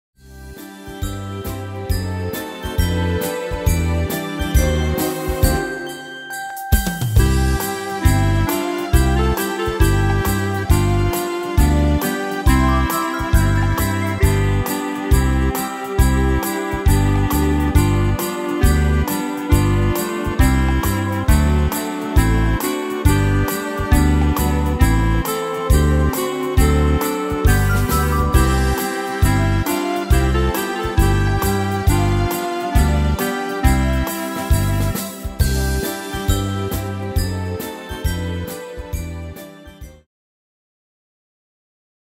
Tempo: 136 / Tonart: C-Dur